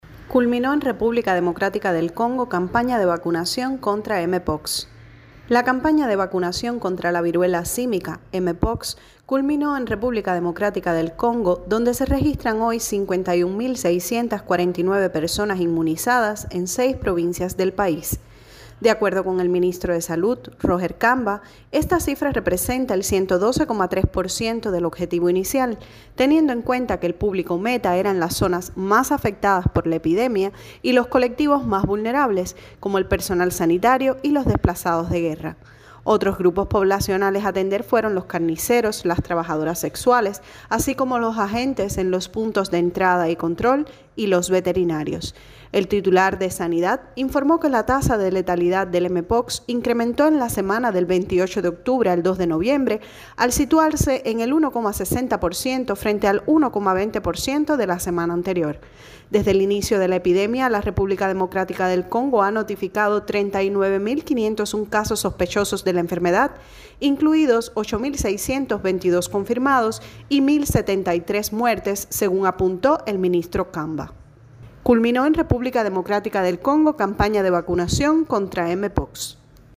desde Kinshasa